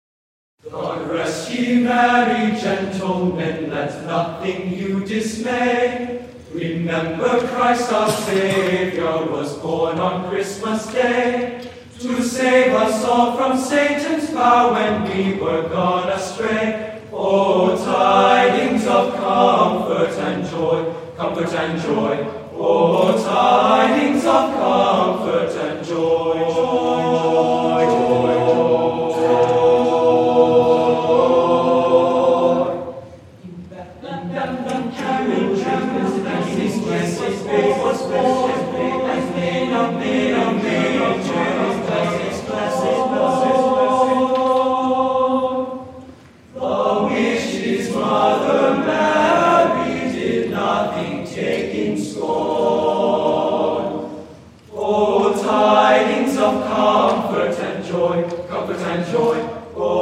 annual winter concert
a mixture of classical and holiday music